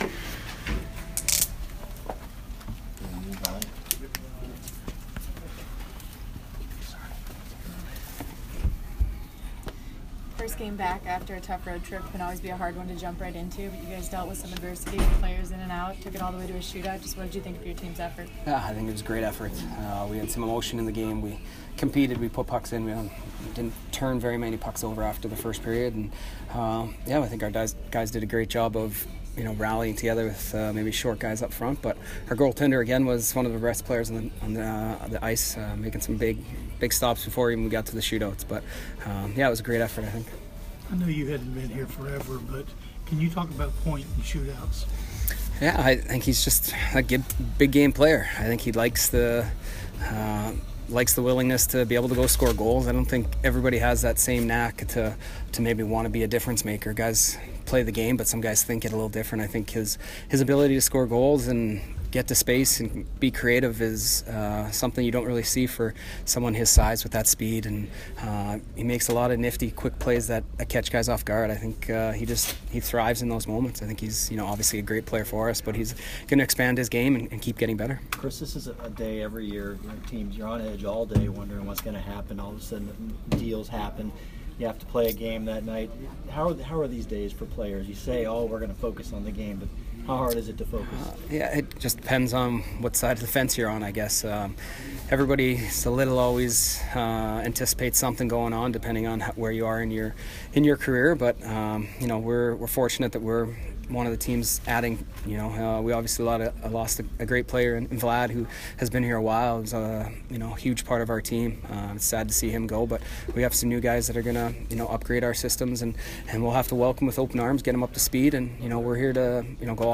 Chris Kunitz post-game 2/26